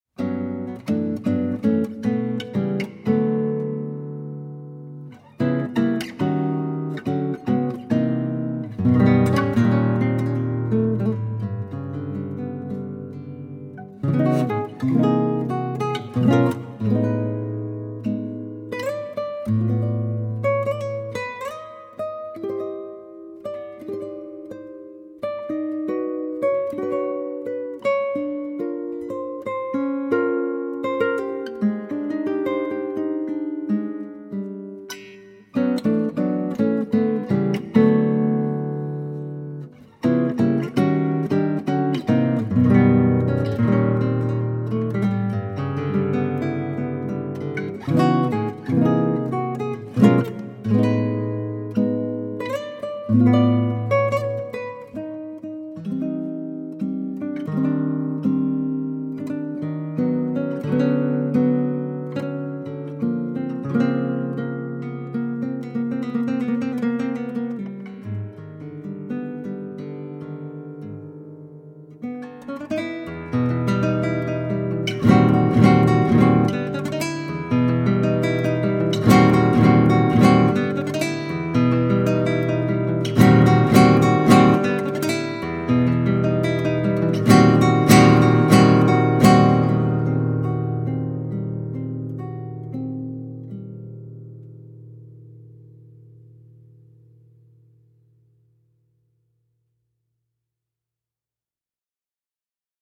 Instrumentação: violão solo
Tonalidade: D | Gênero: instrumental brasileiro